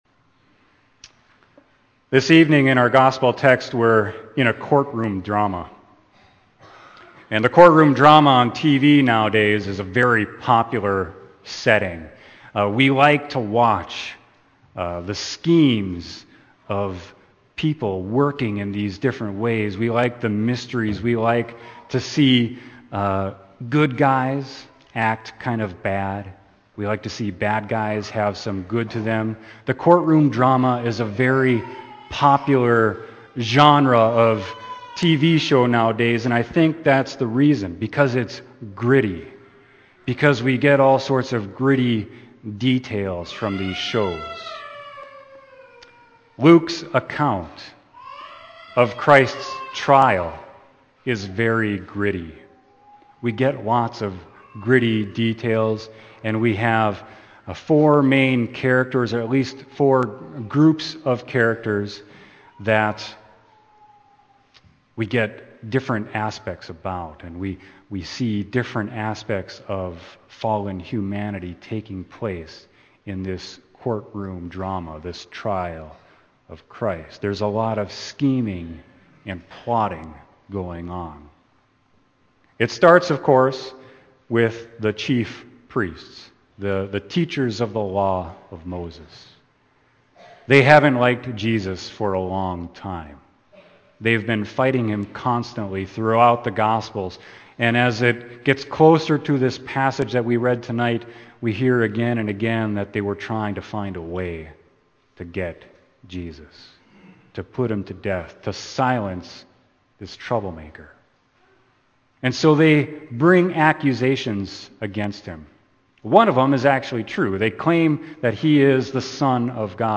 Sermon: Luke 23.1-25